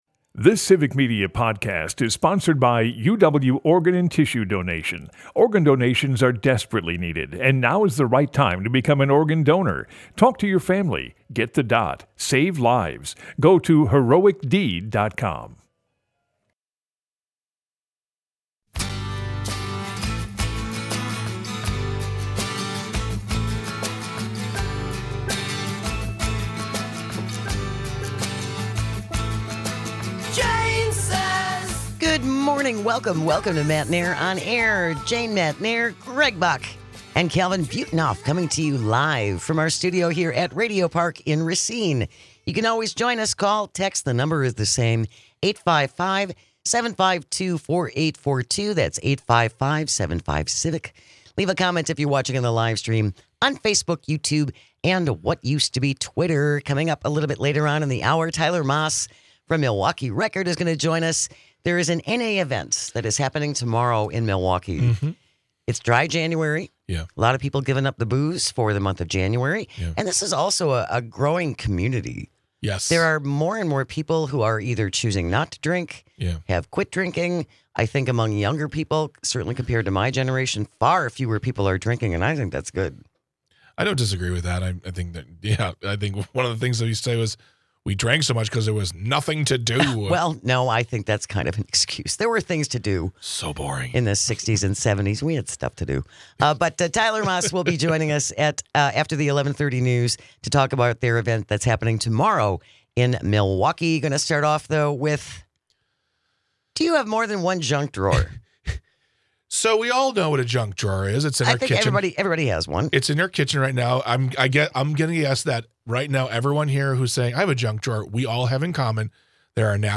They cover all the news that affects you with humor and a unique perspective.